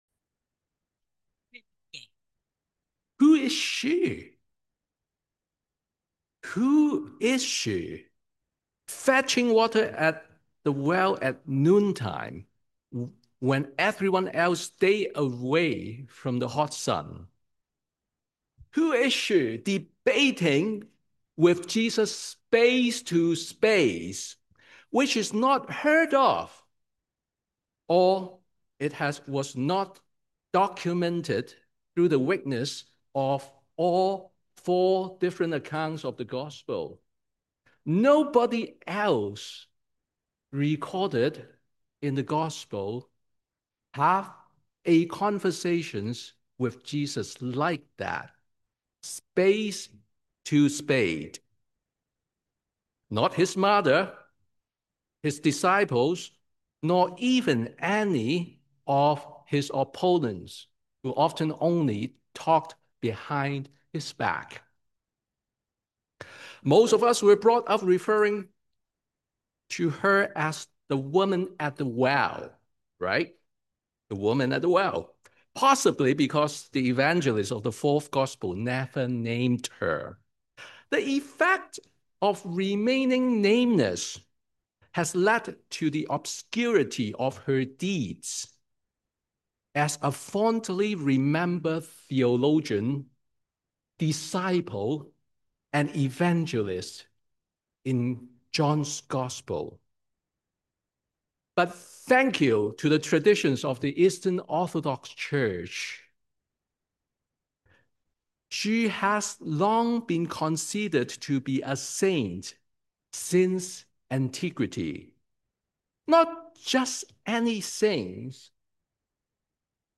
Sermon on the Third Sunday in Lent